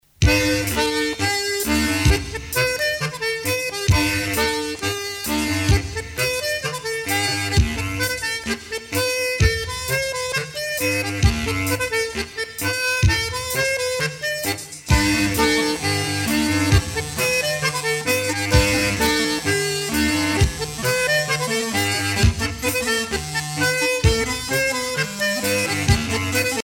danse : pas de quatre
Pièce musicale éditée